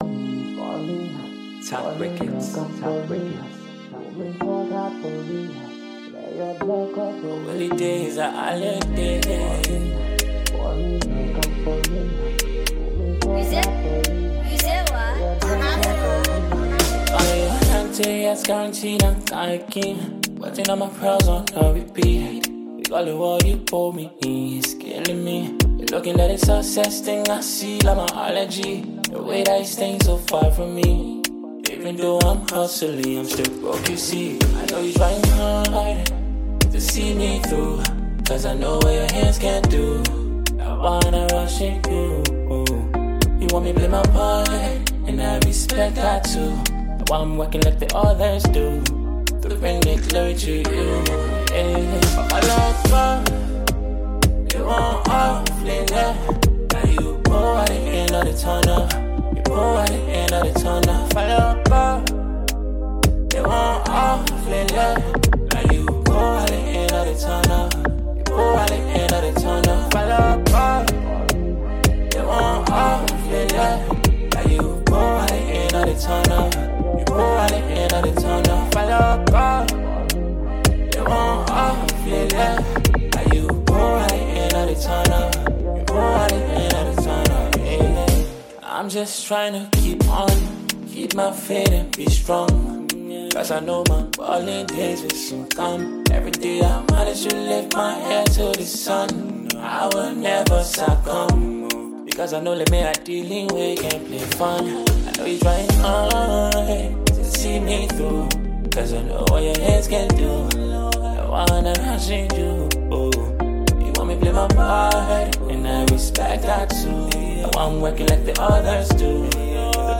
A Liberian multi-talented singer and songwriter